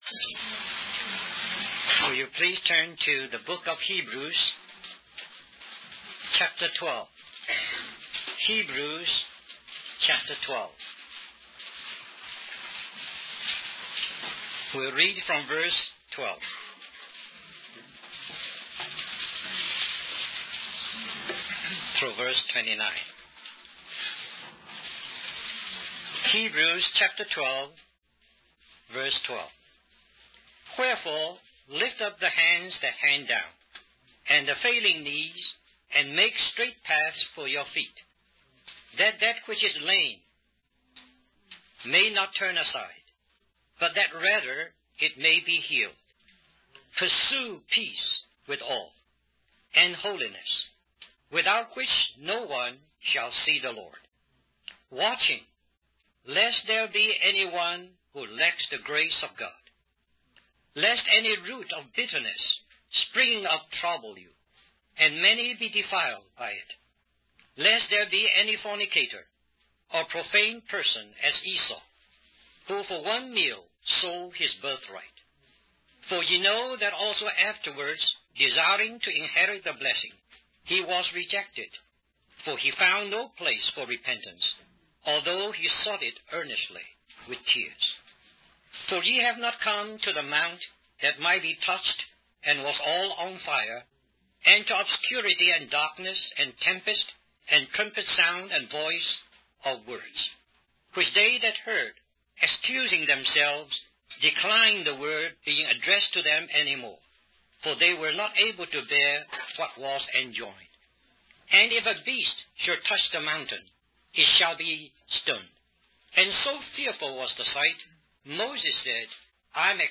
In this sermon, the speaker emphasizes the importance of not falling short of the grace of God. The grace of God is described as both a calling and a supply, calling us into all that God is and supplying us with all that we need. The speaker warns against bitterness and murmuring, as it can spread and defile the whole community of believers.